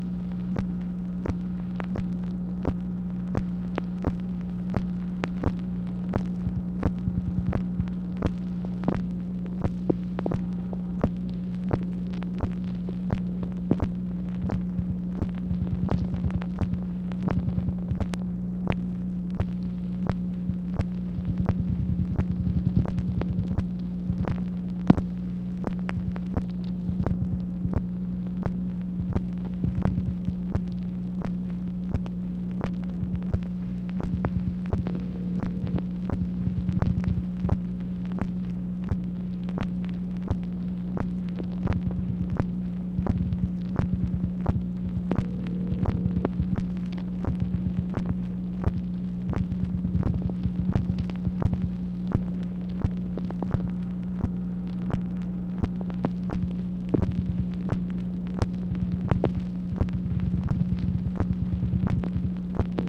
MACHINE NOISE, July 25, 1966
Secret White House Tapes | Lyndon B. Johnson Presidency